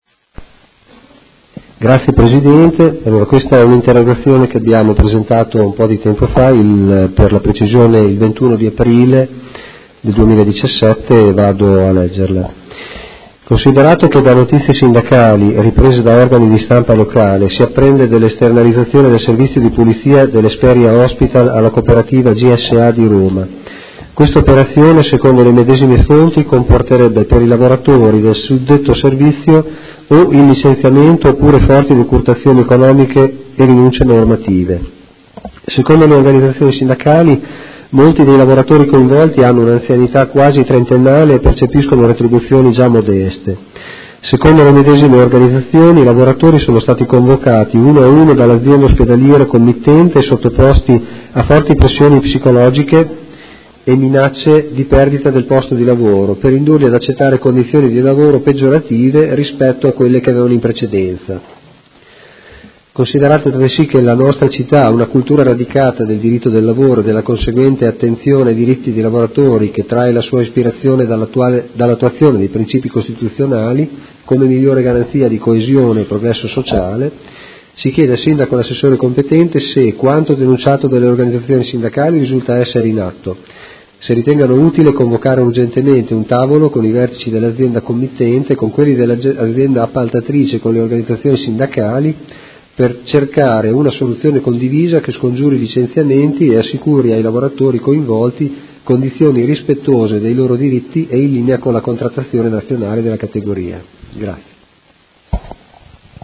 Seduta del 25/05/2017 Interrogazione dei Consiglieri Malferrari e Cugusi (Art.1-MDP) avente per oggetto: Esternalizzazione servizio di pulizia Hesperia Hospital con licenziamenti o peggioramento condizioni di lavoro
Audio Consiglio Comunale